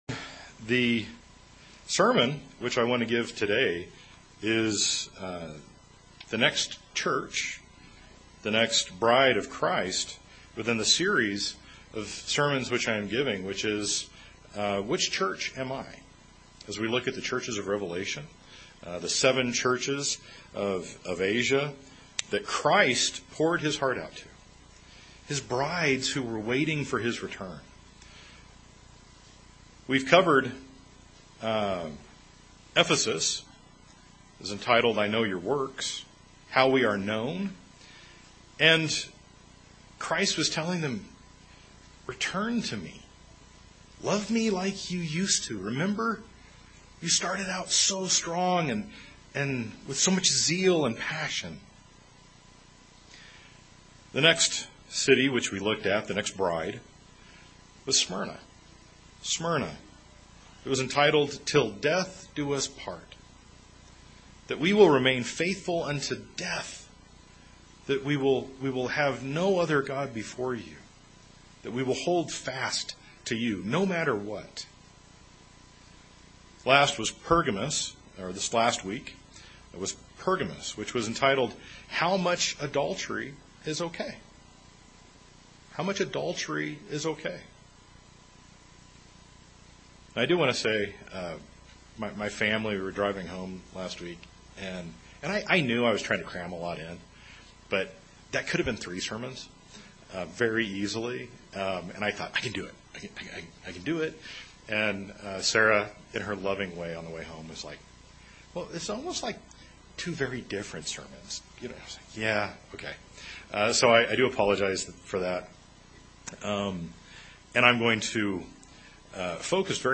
This sermon is the fifth in the series of "Which Church Am I?".